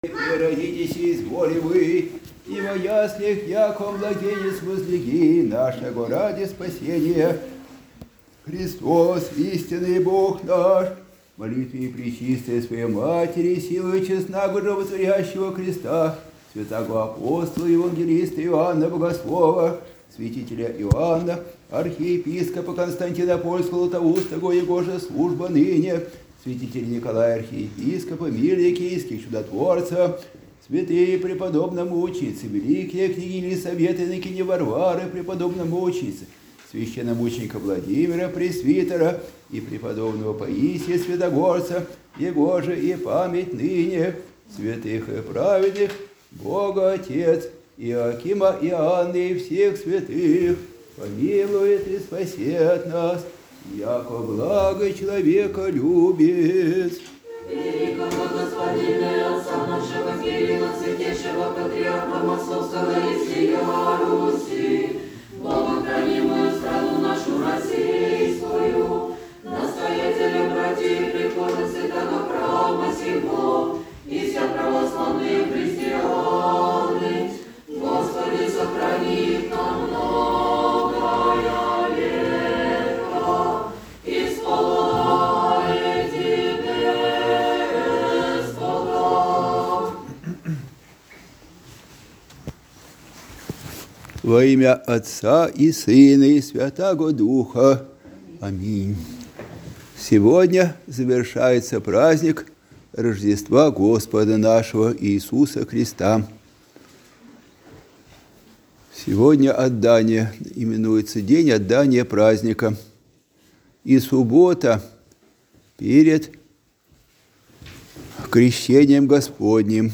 Проповедь прот.